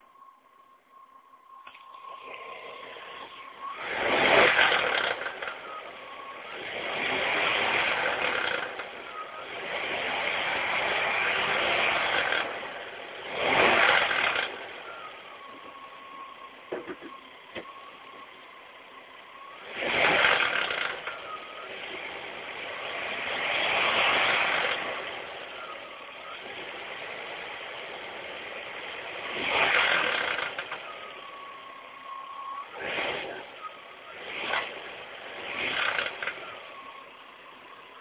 Seit heute morgen höre ich ein Klappern unter dem Auto Richtung Motor/Auspuff sobald man im Stand das Gas wegnimmt.
z.B. Fahrzeug steht - Kurz das Gaspedal antippen bis er so 1500 - 2000 U/min erreicht und beim Loslassen des Gaspedals klappert es.
Ich hab mal versucht das mit dem Handy aufzunehmen, leider klingt es da so derb (durch die Komprimierung) das man denkt das Auto zerlegt sich grad.
So, hier mal der Sound - falls jemand meckert - ist leider mit dem MobilePhone aufgenommen worden - daher leider kein THX.
Klappersound-Krümmer-Bank1
bei deinem sound ist mir aufgefallen das sobald du vom gas gehst so ein leichtes Pfeifen zu hören ist ,das hat der 3,2 meiner Frau auch und Opel weiss nicht wo das Geräusch herkommt, Zahnriemen und Keilrippenriemen und alle Rollen wurden auch schon getauscht, weiss jemand wo dieses Pfeifen im Stand herkommt.